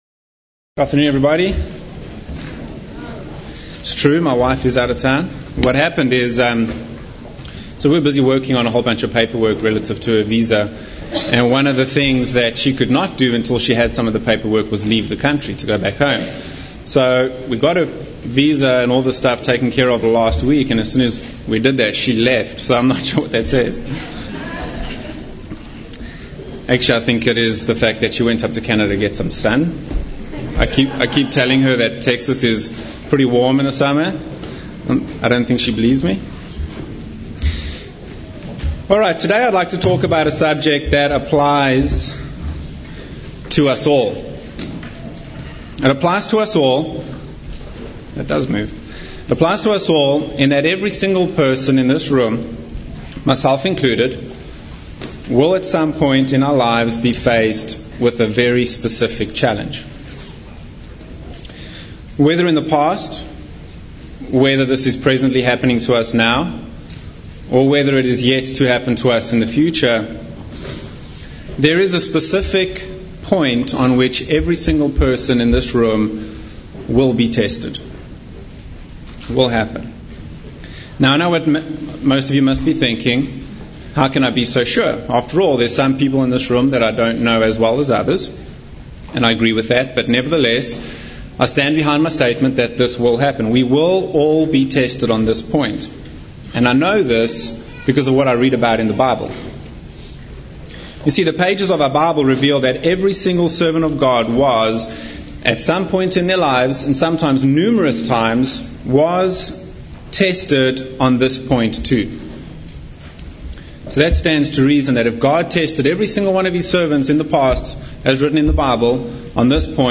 This sermon will break the subject of Biblical-courage down into three primary attributes to reveal why it is vital for our spiritual lives, and pleasing to God.
Given in Dallas, TX